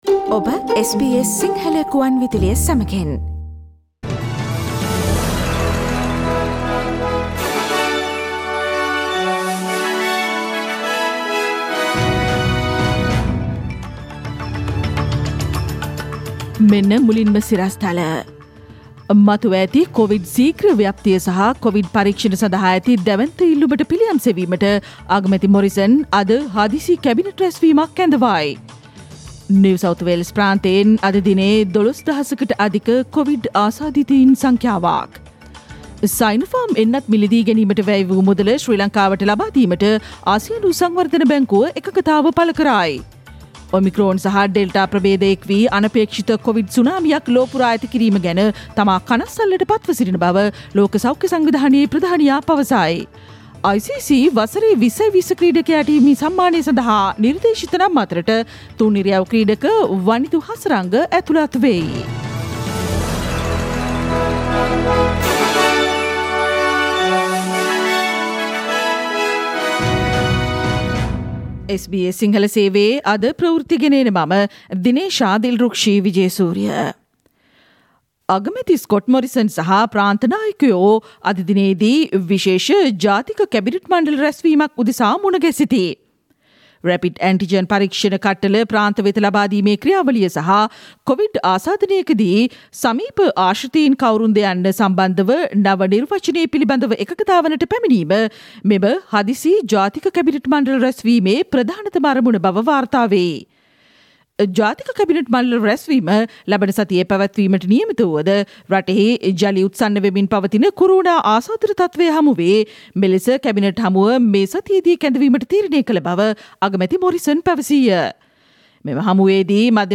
Click on the speaker icon on the image above to listen to the SBS Sinhala Radio news bulletin on Thursday 30 December 2021